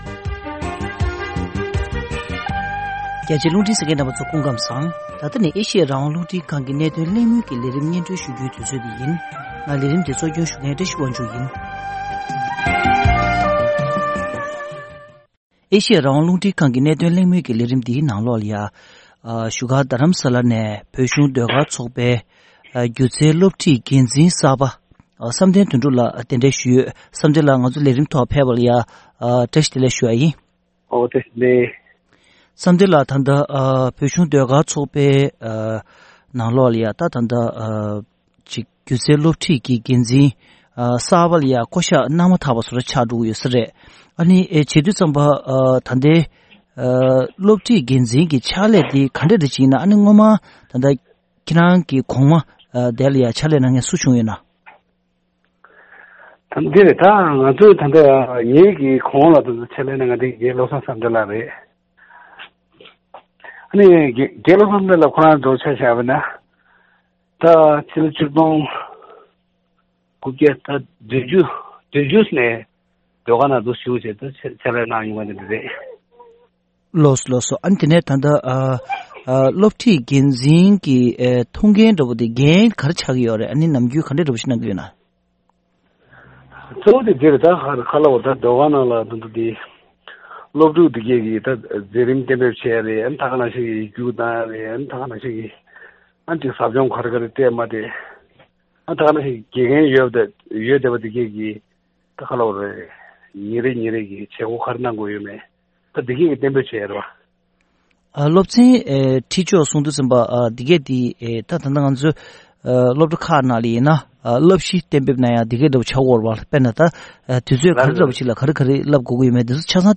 ཐེངས་འདིའི་གནད་དོན་གླེང་མོལ་གྱི་ལས་རིམ་ནང་དུ།